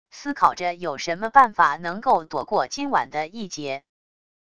思考着有什么办法能够躲过今晚的一劫wav音频生成系统WAV Audio Player